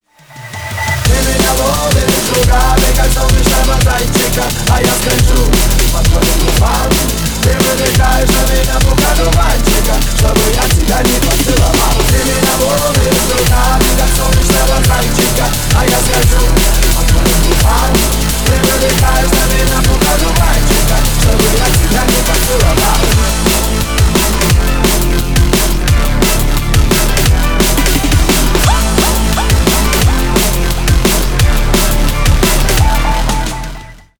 Ремикс # Поп Музыка # Танцевальные